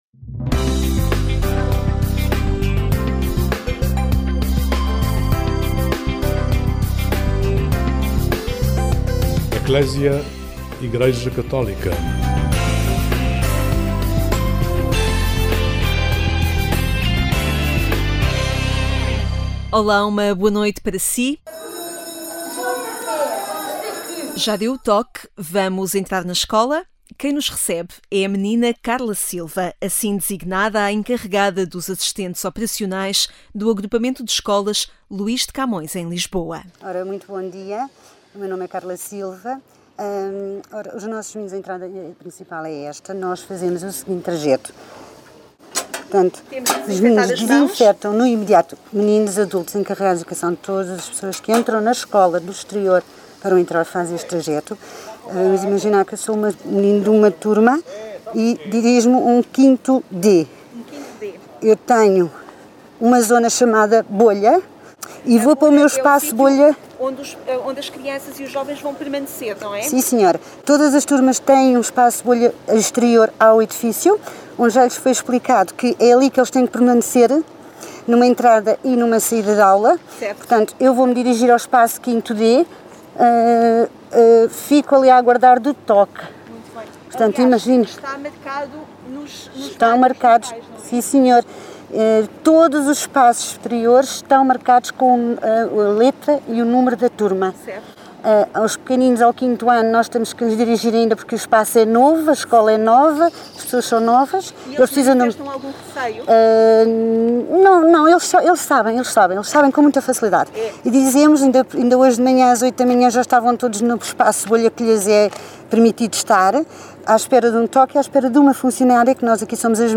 Acompanhamos na Escola Luís de Camões, em Lisboa, o regresso às aulas em mais um início de ano letivo, mas envolto em cuidados, distância, máscaras e muito álcool-gel. Junto de quem acompanha diariamente os alunos, entre o 5º e o 9º anos, percebemos desafios mas também a confiança de quem quer chegar ao final do ano com toda a comunidade educativa junta.